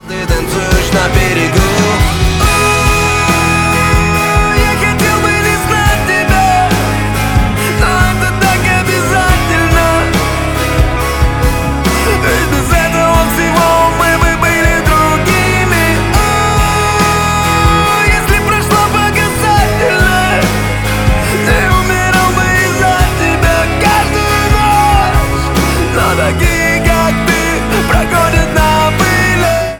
• Качество: 128, Stereo
гитара
лирика
душевные
скрипка
романтичные
эмоциональные